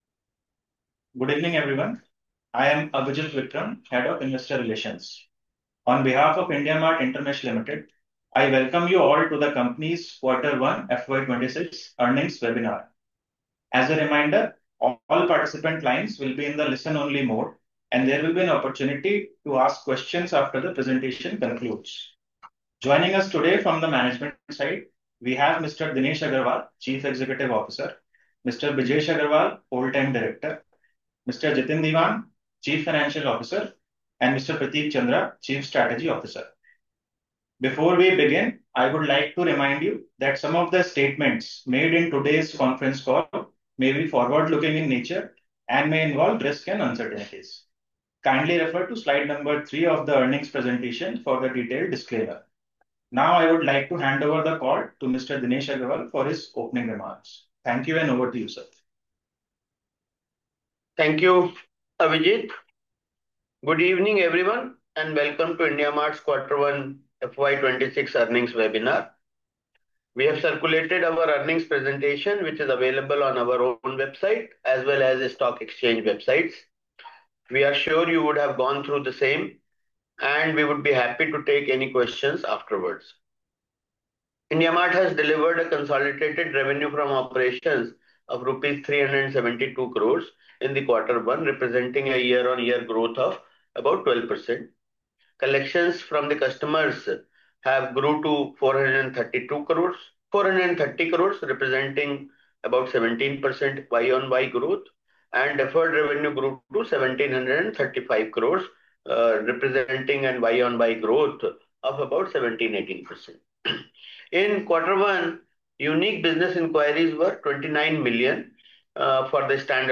IndiaMART_Q1_FY26_Earnings_Webinar_Audio_Recording.m4a